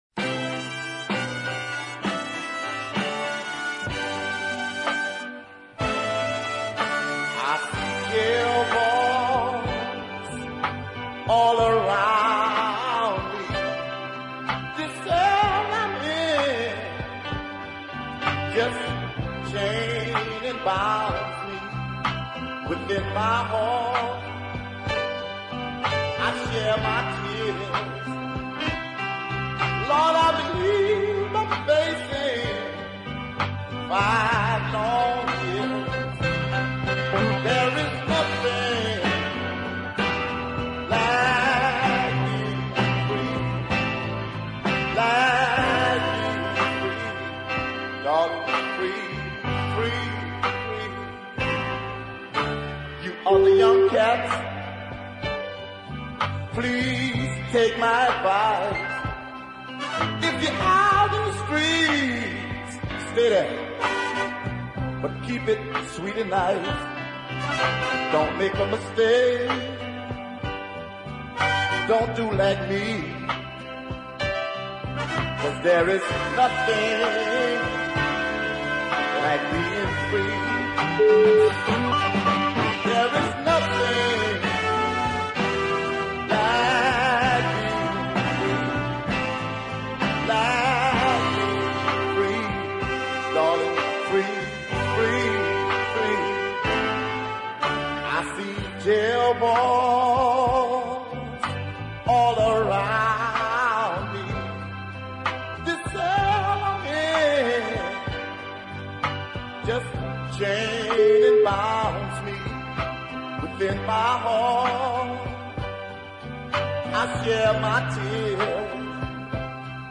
I like the horn charts and the chord changes
check out those occasional falsetto phrases.